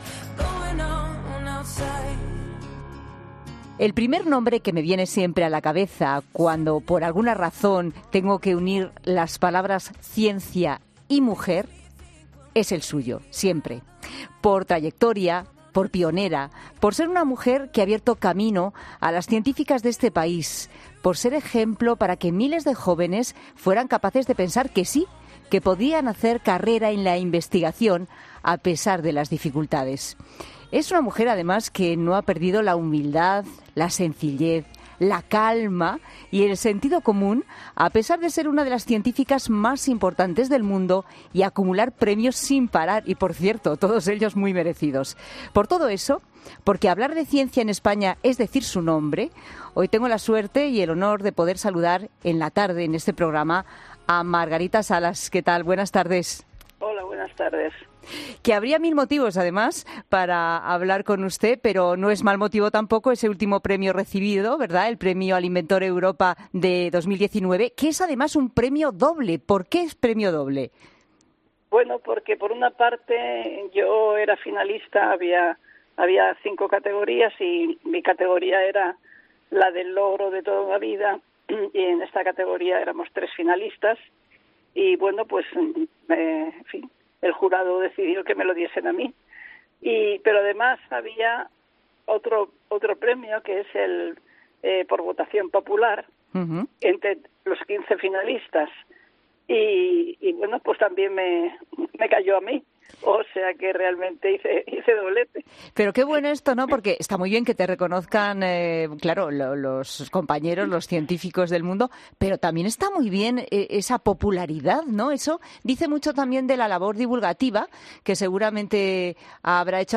Hoy, ha estado en los micrófonos de 'La Tarde' de COPE Margarita Salas, ganadora del premio inventor europeo 2019 por su trayectoria en la secuenciación del ADN.